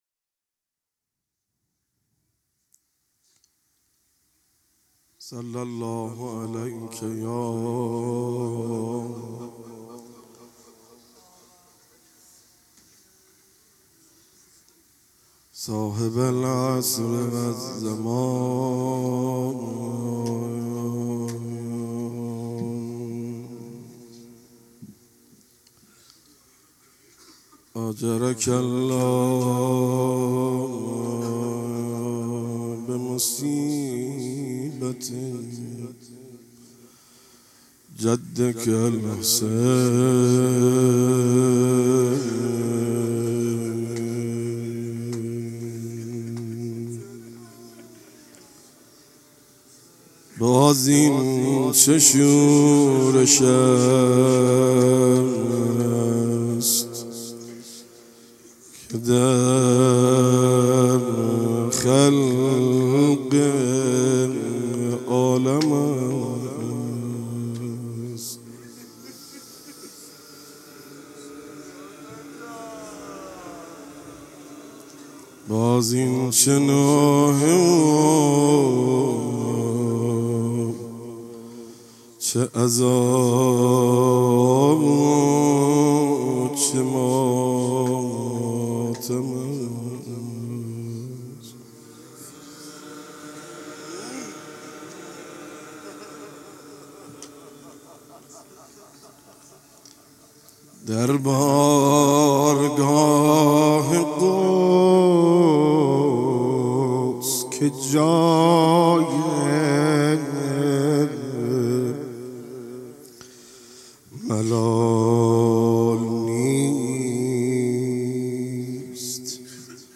شب اول محرم97 - روضه